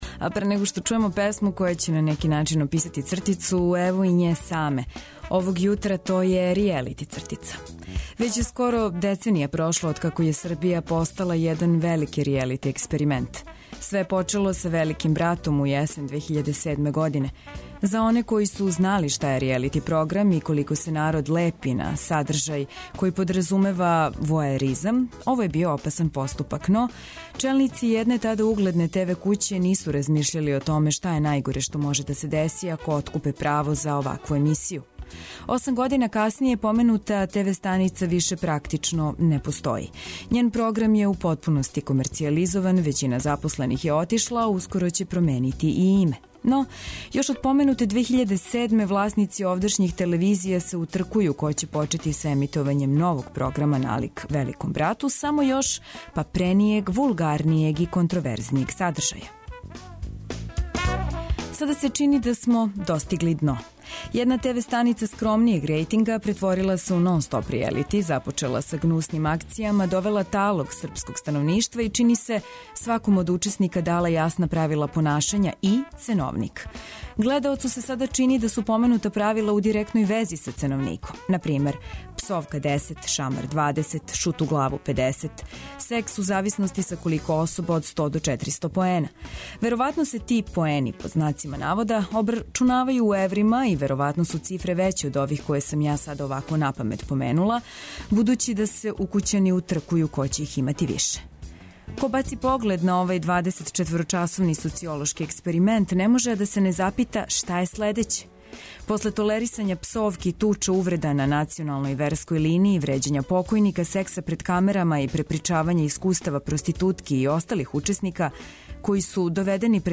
Овај Устанак ће вас размрдати добром музиком, расанити важним информацијама и новим вестима, и показати вам да, иако смо радио, имамо Биоскоп!